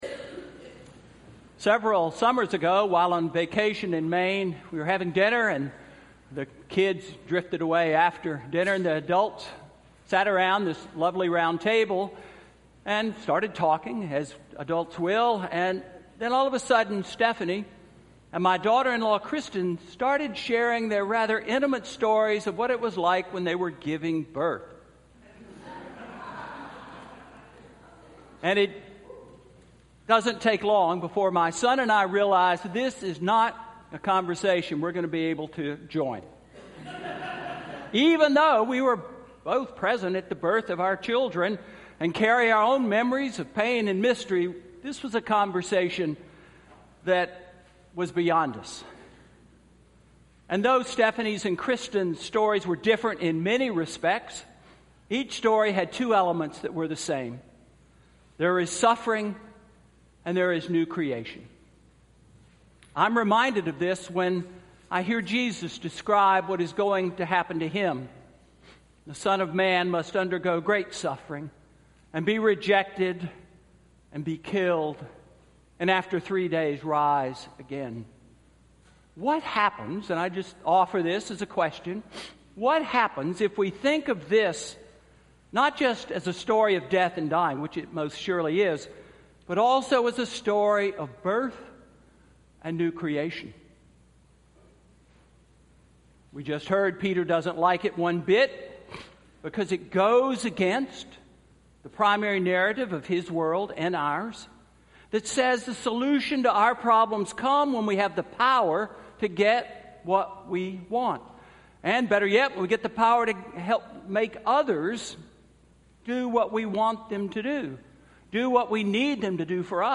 Sermon–February 25, 2018